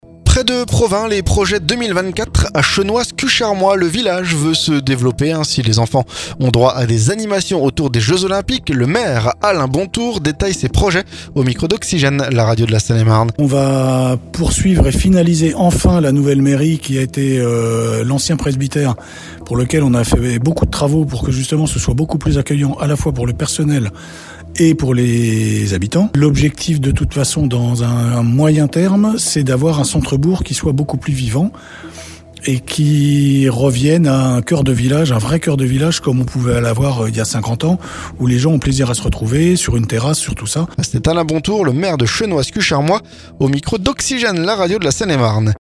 Si les enfants ont droit à des animations autour des Jeux Olympiques, le maire Alain Bontour détaille ses projets au micro d'Oxygène, la radio de la Seine-et-Marne.